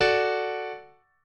piano5_12.ogg